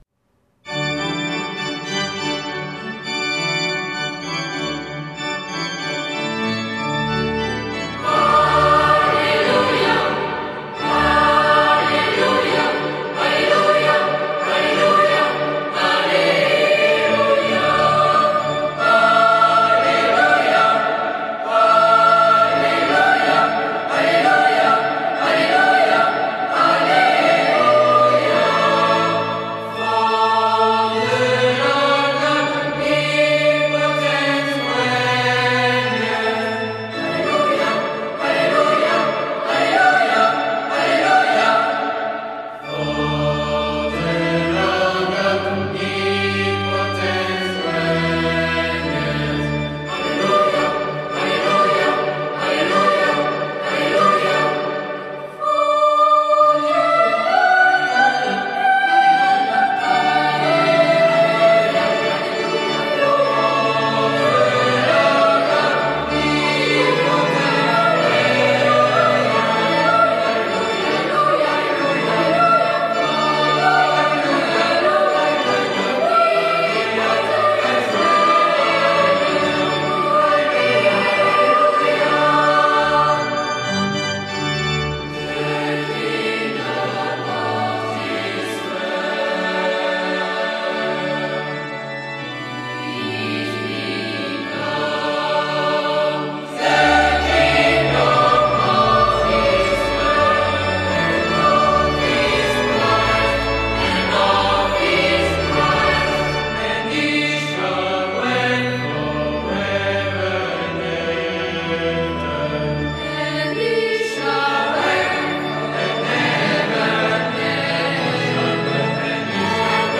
Chorale Sainte-Euverte d’Orléans. Orgue